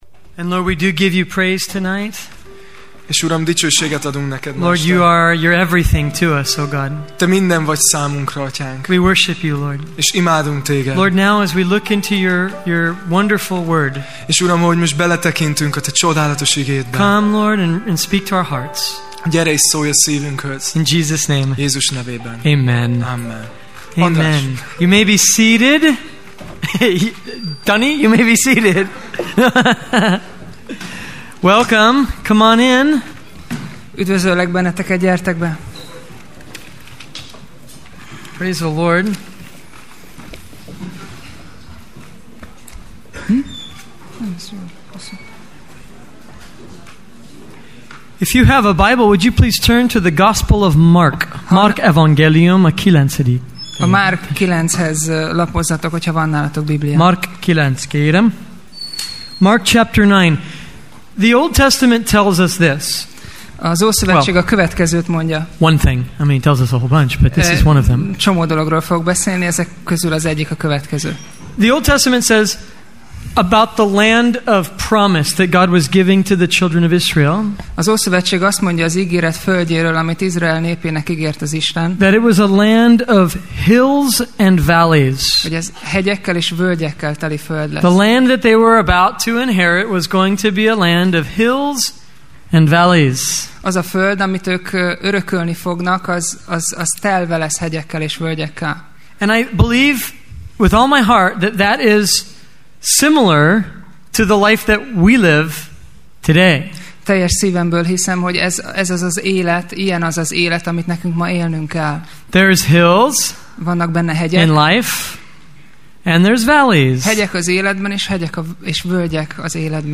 Sorozat: Tematikus tanítás
Alkalom: Vasárnap Este